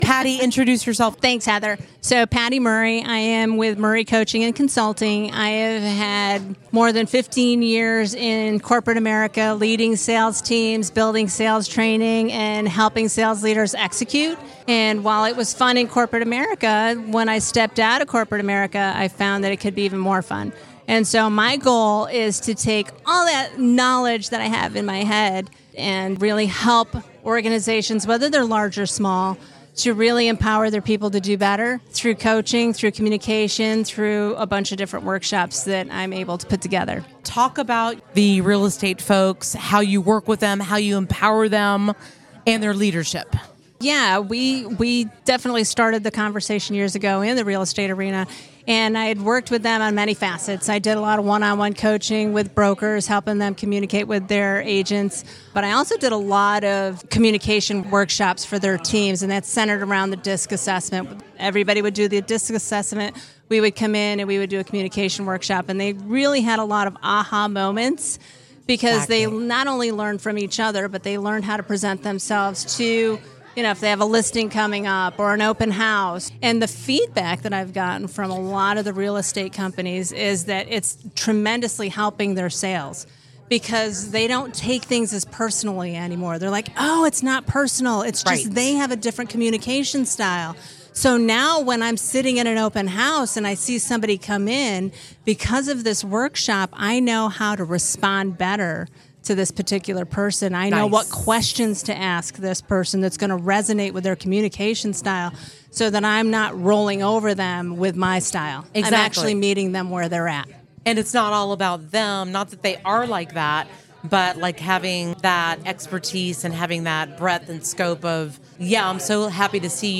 Business RadioX was on site to interview and highlight several of the business professionals attending the event.